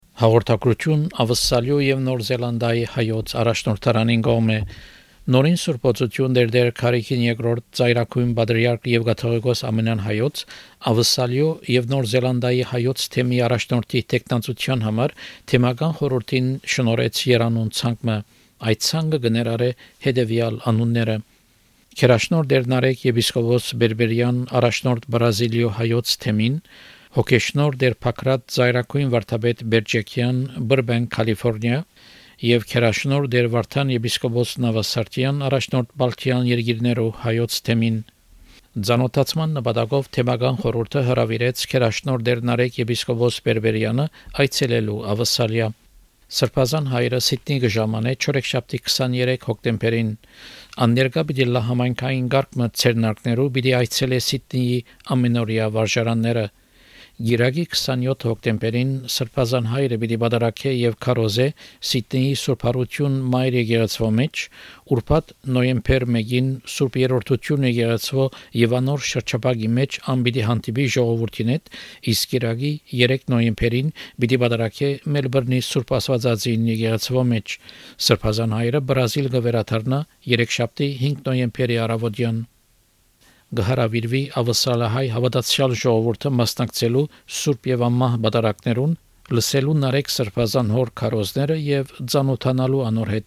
Community announcement from the Diocese of the Armenian Church of Australia & New Zealand.